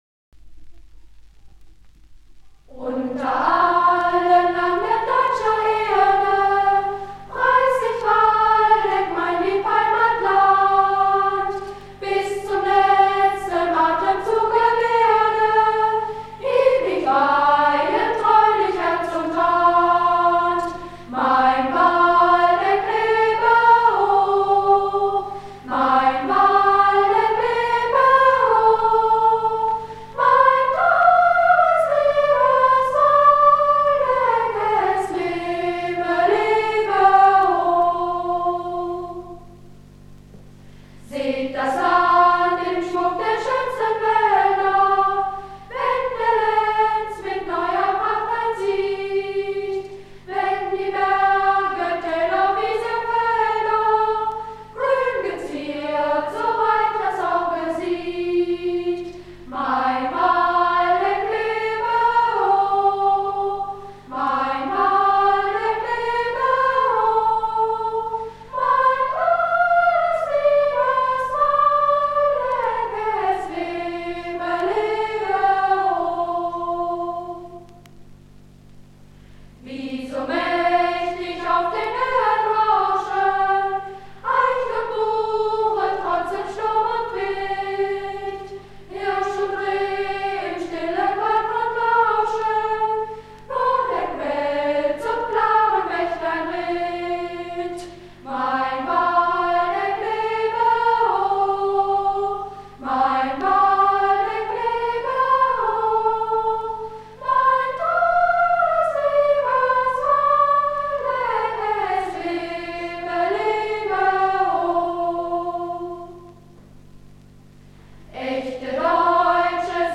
Waldecker Lied (Urform alle 6 Strophen) Realschule Korbach (1965)